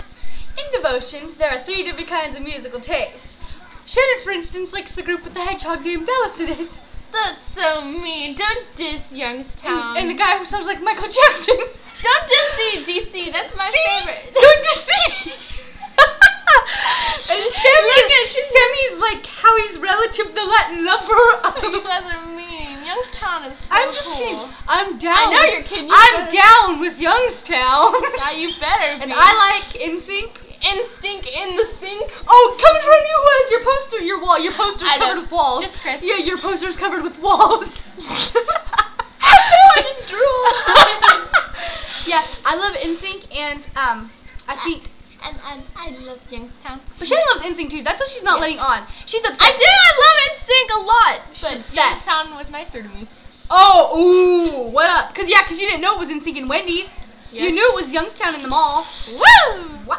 We were talking about different musical tastes.....pretty funny. We are just joking.